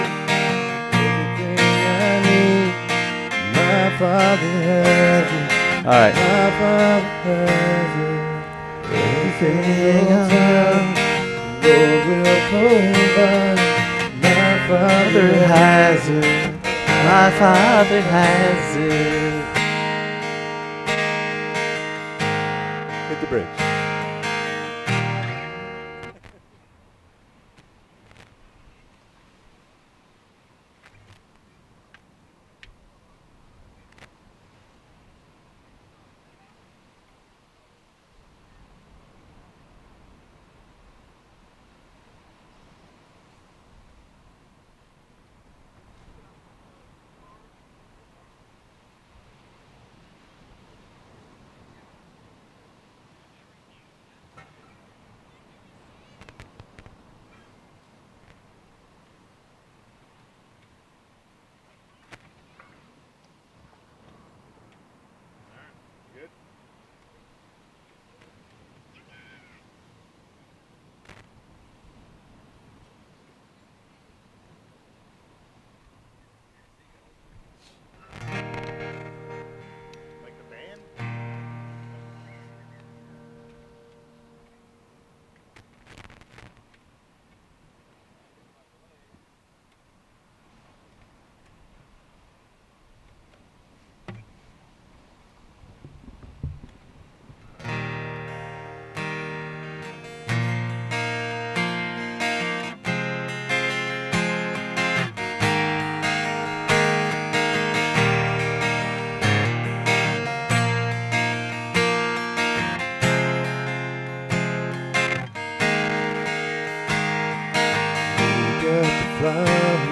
SERMON DESCRIPTION Today we will examine an encounter between Jesus and a young man who had many possessions.